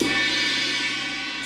Crash (Devil In A New Dress).wav